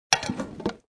Descarga de Sonidos mp3 Gratis: hojalata 1.